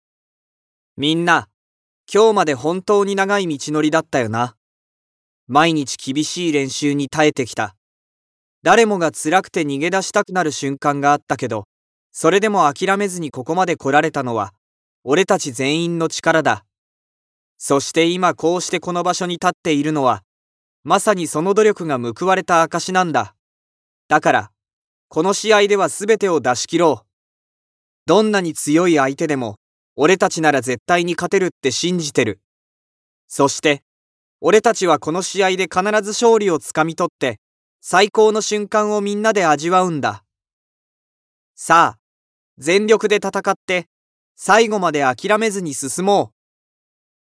感情を込めずに音読してみましょう。
【素読み】